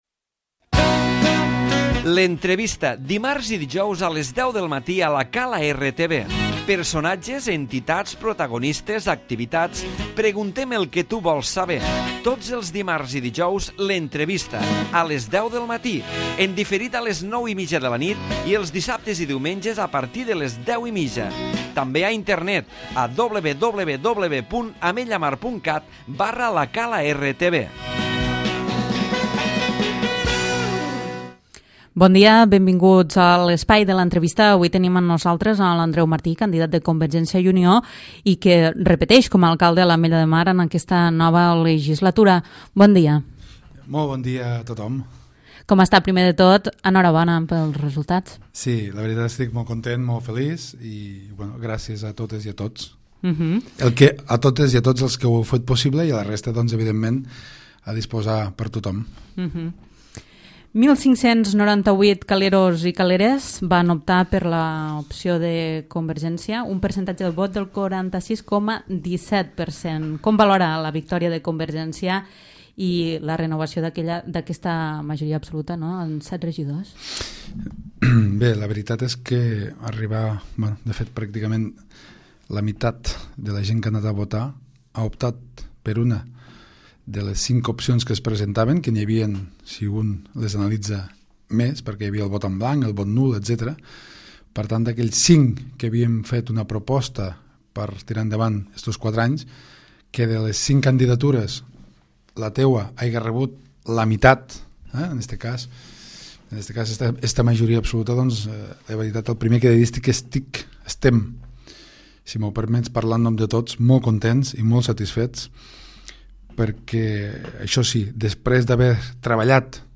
L'Entrevista
Avui hem entrevistat a l'Andreu Martí candidat de CiU. Martí serà l'alcalde l'Ametlla de Mar en aquesta nova legislatura.